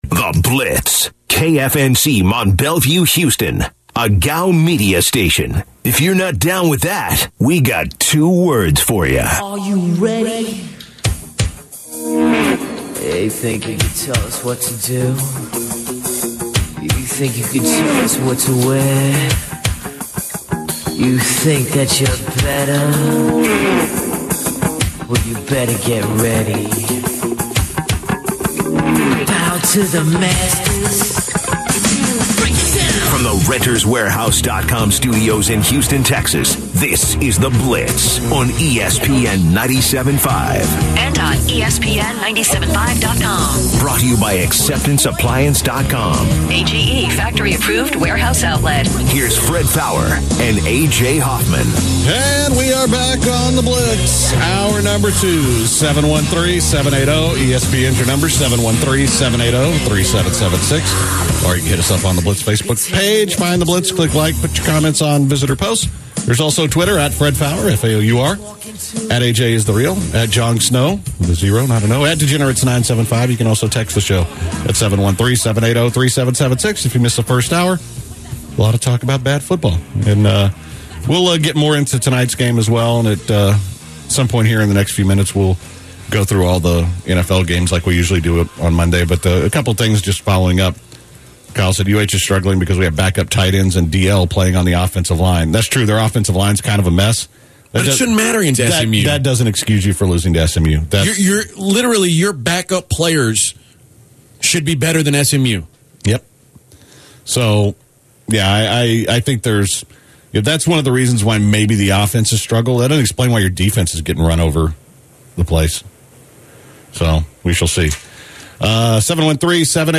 The guys started the hour off with NFL talk. They answered some questions from callers.